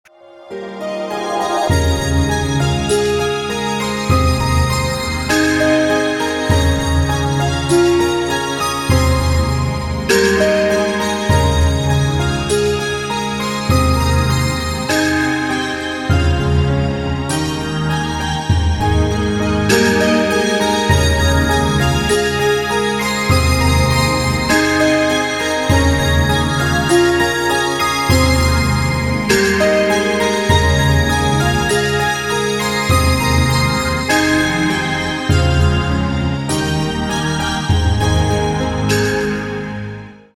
• Качество: 192, Stereo
спокойные
без слов
инструментальные